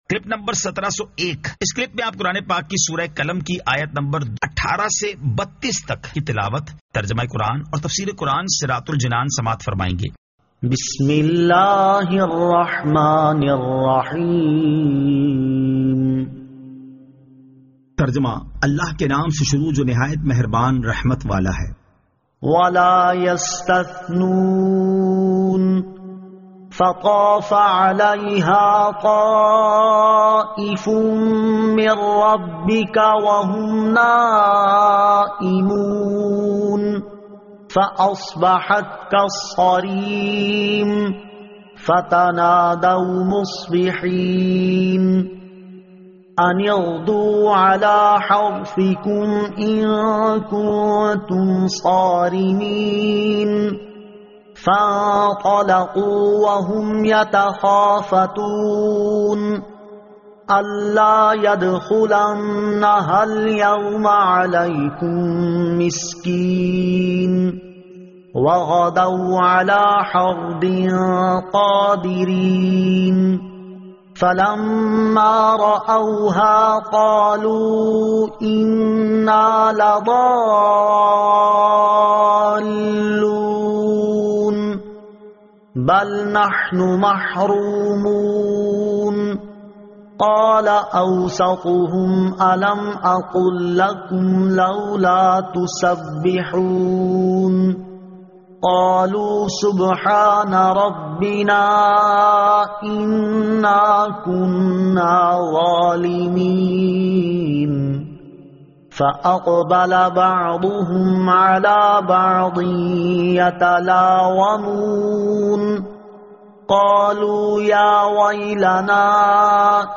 Surah Al-Qalam 18 To 32 Tilawat , Tarjama , Tafseer